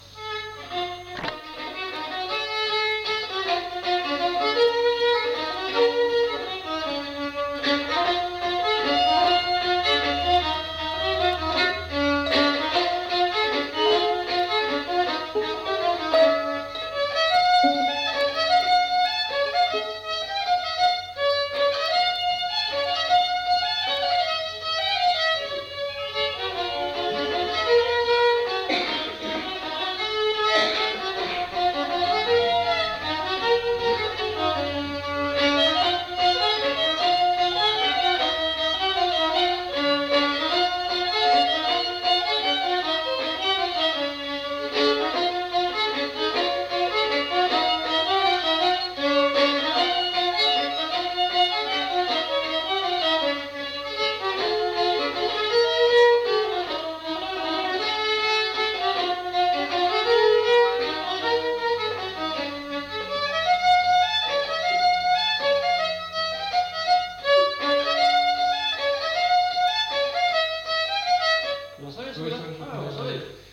Aire culturelle : Petites-Landes
Lieu : Roquefort
Genre : morceau instrumental
Instrument de musique : violon
Danse : mazurka